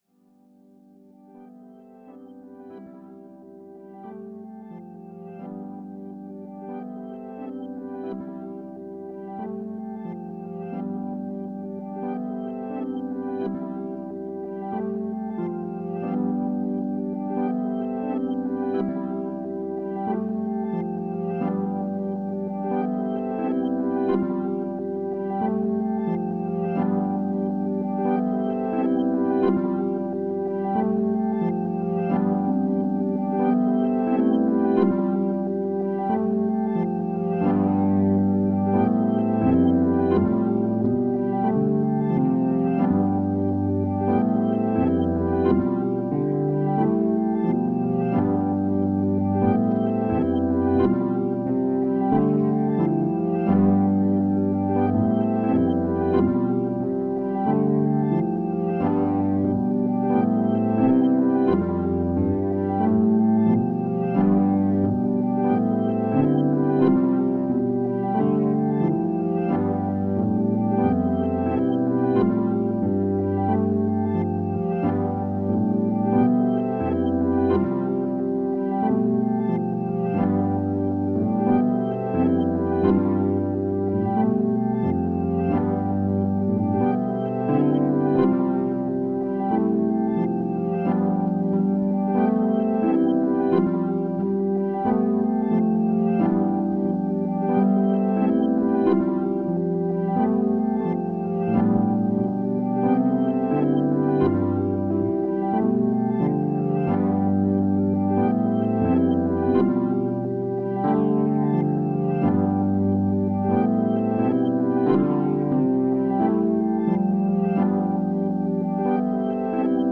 Added a brace for the sustain pedal but then didn’t use it much in this.
Wurlitzer and M13.